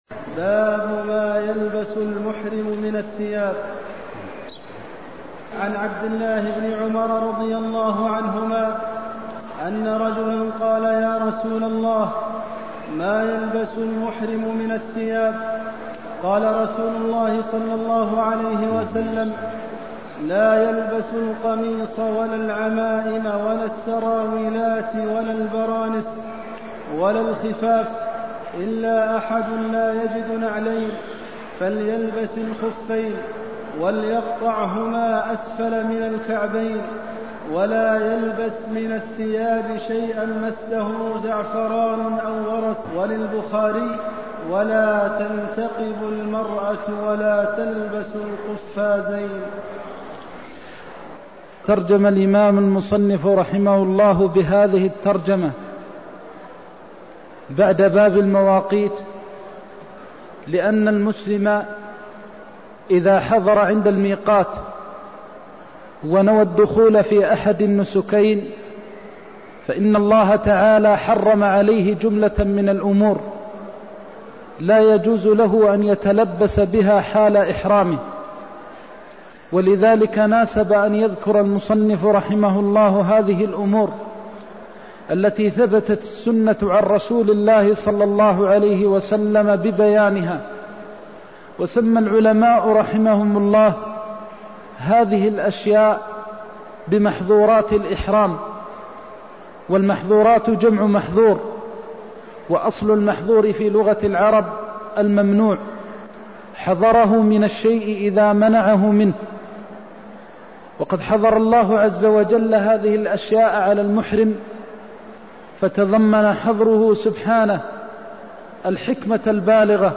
المكان: المسجد النبوي الشيخ: فضيلة الشيخ د. محمد بن محمد المختار فضيلة الشيخ د. محمد بن محمد المختار ما يلبس المحرم من الثياب (204)